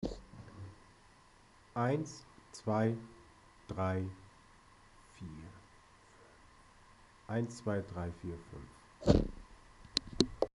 Grundsätzlich rauscht es und die eigentlichen Geräusche wie Stimmen sind vergleichsweise leise.
Die Feiertage hatte ich mal etwas Zeit und habe eine Vergleichsaufnahme gemacht: Abstand zur Kamera ca. 30cm im Raum.
Tonaufnahme - Sony FDR-X3000
Hört sich irgendwie an, als wäre das Mikro "verstopft" und die Kamera muss die Aufnahmeempfindlichkeit hochdrehen.